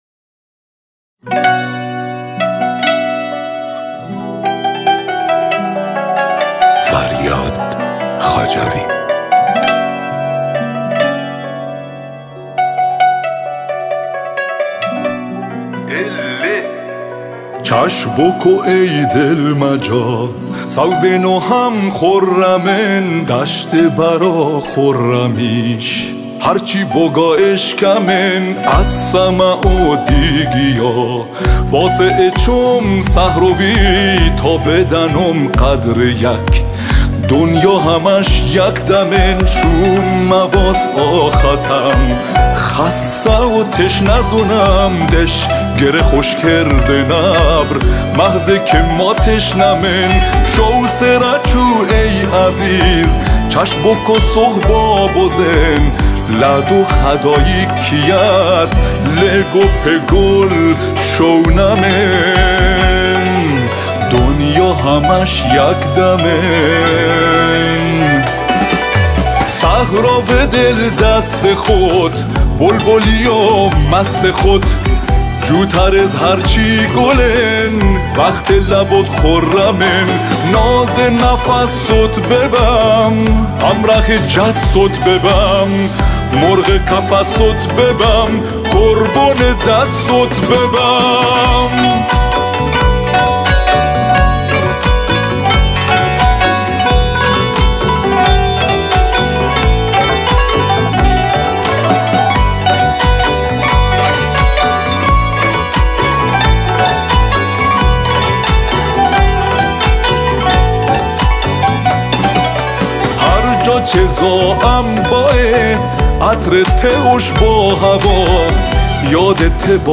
سنتور
ویالون استرینگ